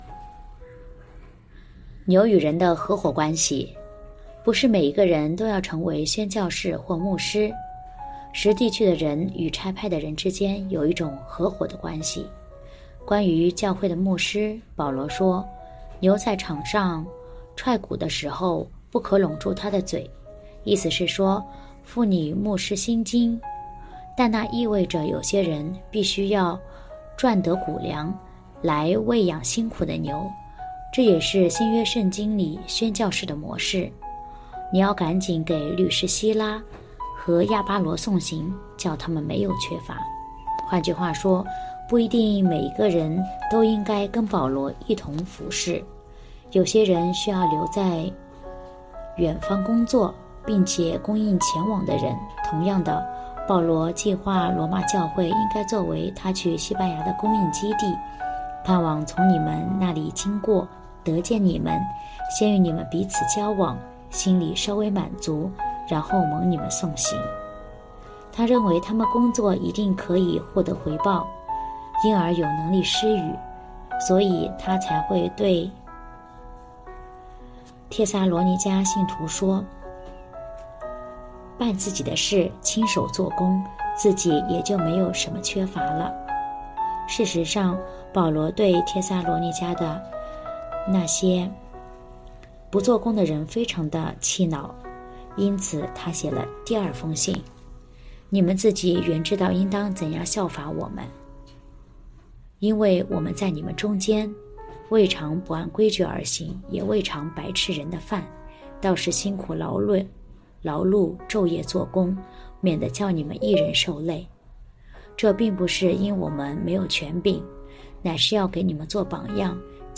2024年7月2日 “伴你读书”，正在为您朗读：《活出热情》 欢迎点击下方音频聆听朗读内容 音频 https